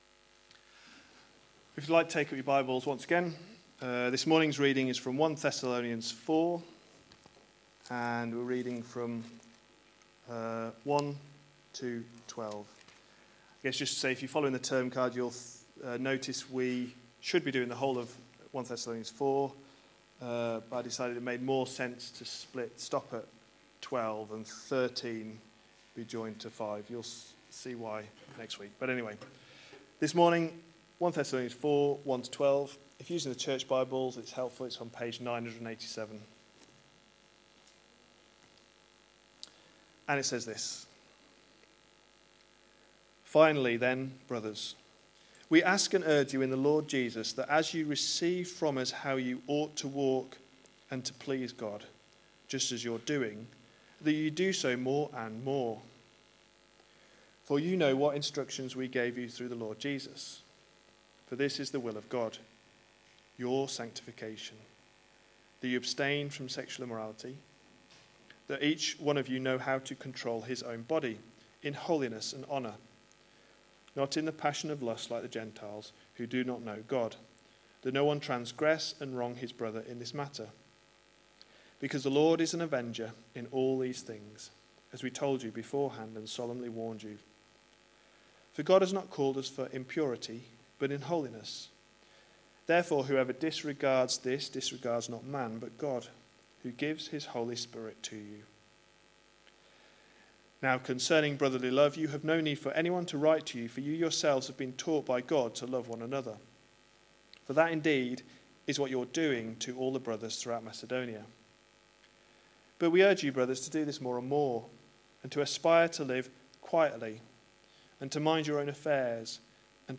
A sermon preached on 29th July, 2018, as part of our 1 Thessalonians series.